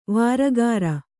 ♪ vāragāra